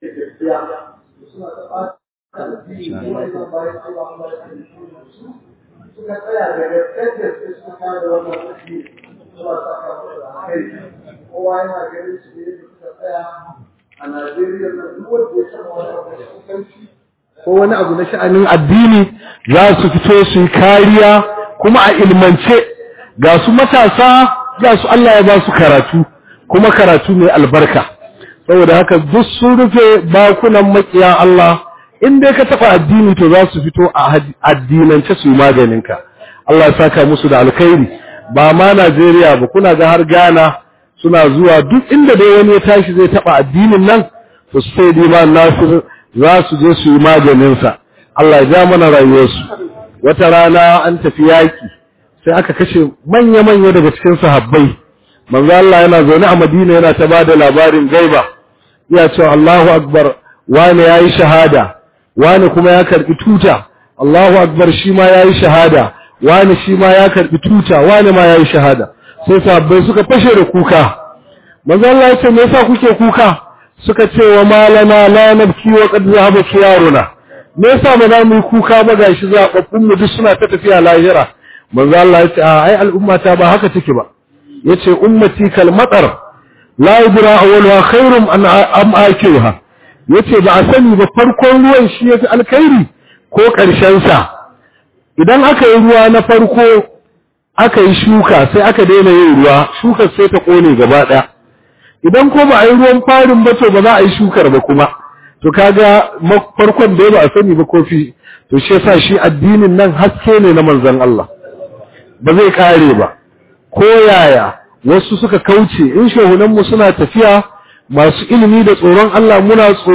009 MAULUDIN ANNABI SAW A Zawiyar Nana Fadimah Gombe.mp3